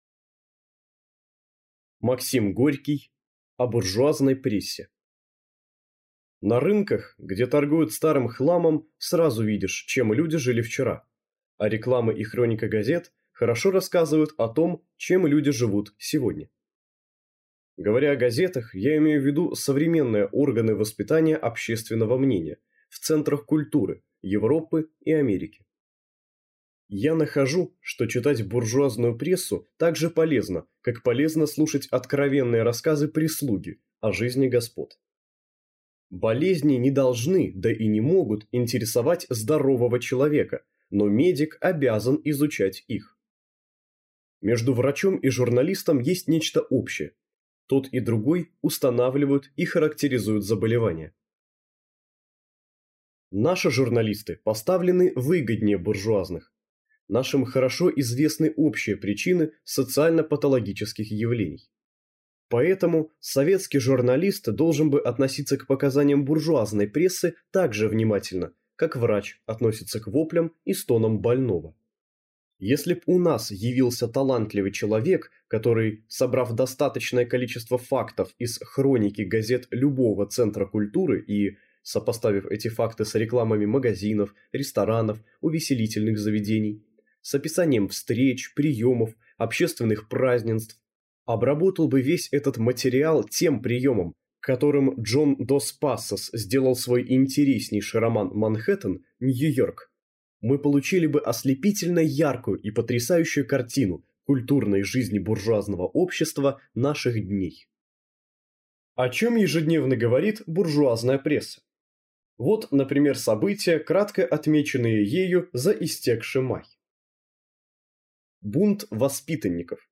Аудиокнига О буржуазной прессе | Библиотека аудиокниг